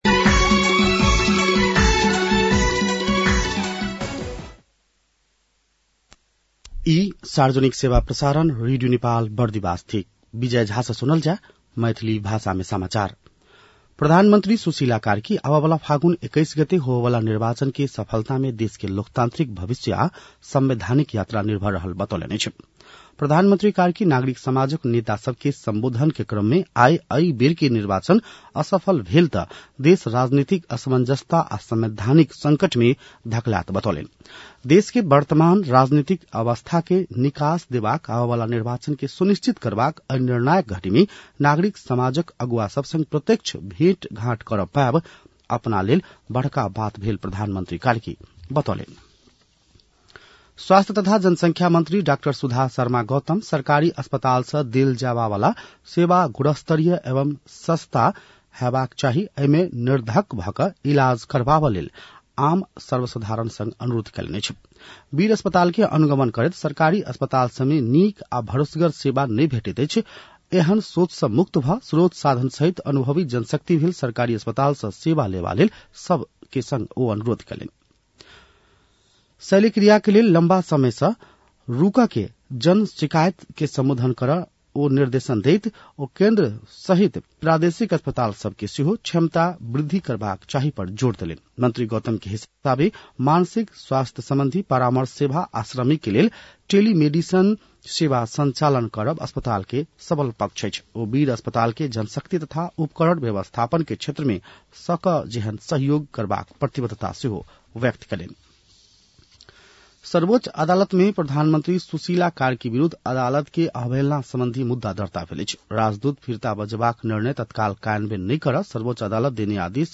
मैथिली भाषामा समाचार : १ मंसिर , २०८२
6.-pm-maithali-news-1-2.mp3